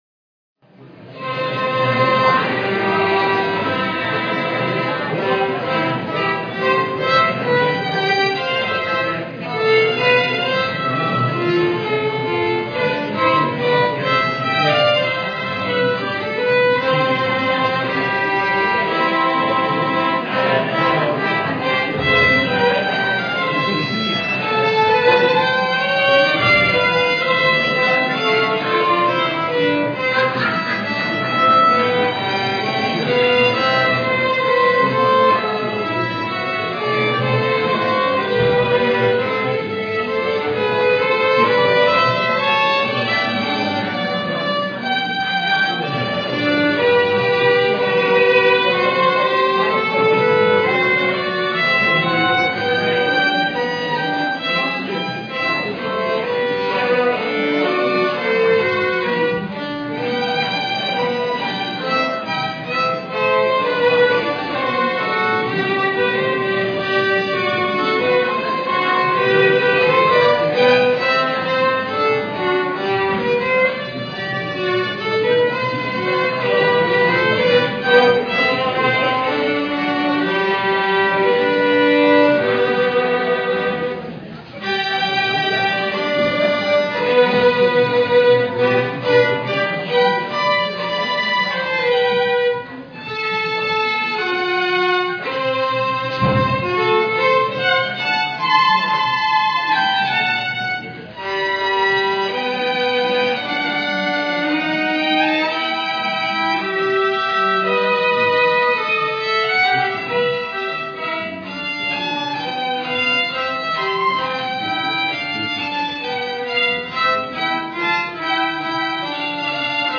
PLAY "Come to Calvary" Musical, Apr 10, 2009 "Everlasting Life" Copyright � 2007 Experience Worship music Publishing/Belden Street Music Publishing/ASCAP (admin.